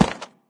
grassstone2.ogg